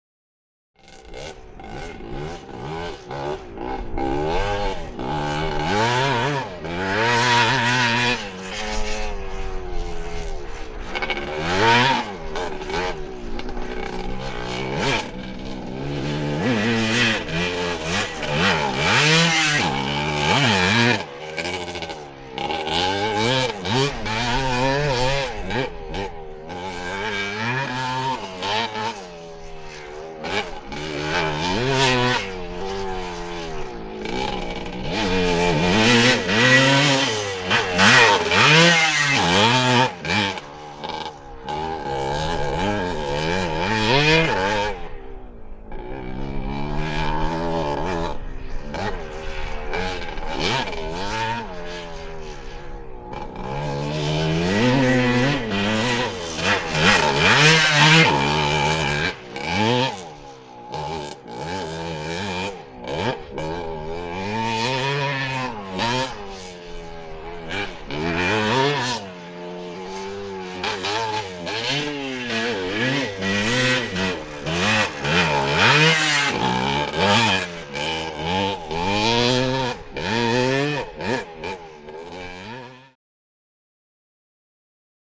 250 Dirt Bike
Motorcycle; On Circuit; Three Scrambles Motorbikes Round Circuit 200 Yards From Mic. Some Voices Break Through Now And Again.